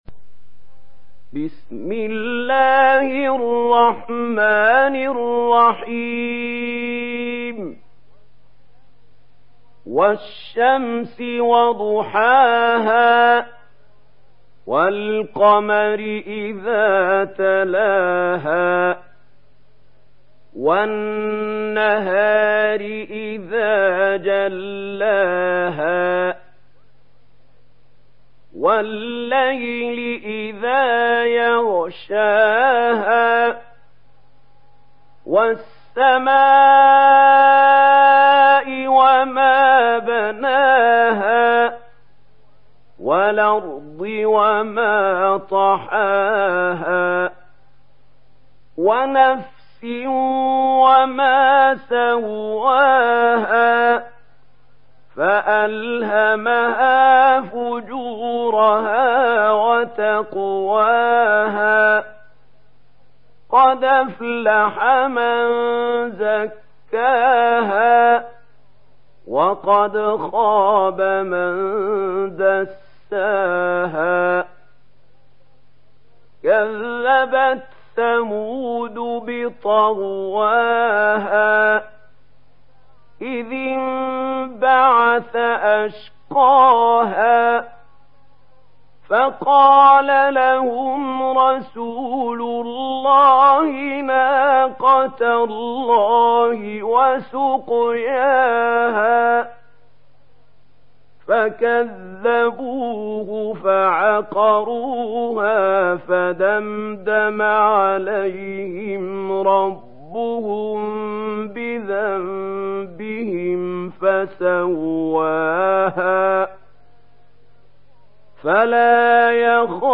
Şems Suresi mp3 İndir Mahmoud Khalil Al Hussary (Riwayat Warsh)